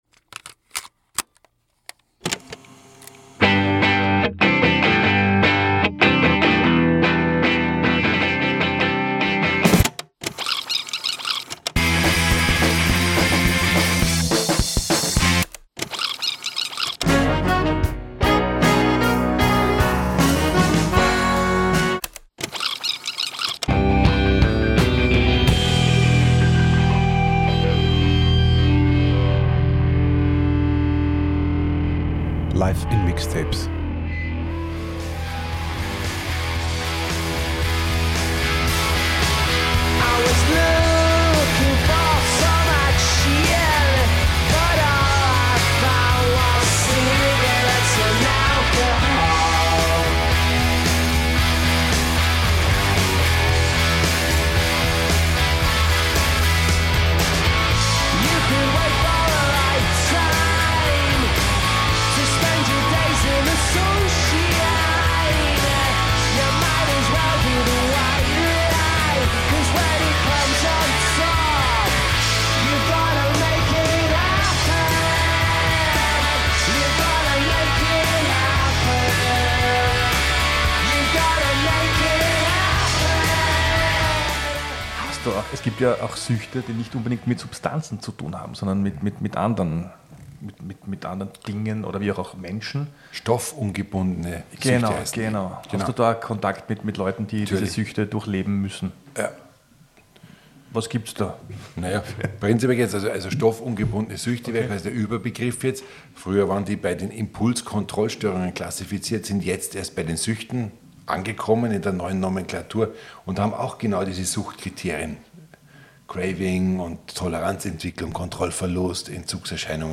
Diesmal geht es um stoffungebundene Süchte (Internetsucht, Spielsucht, Essstörungen) und um illegale Drogen (Cannabis, LSD, Kokain und Heroin). Und dazu wie immer die passenden Songs.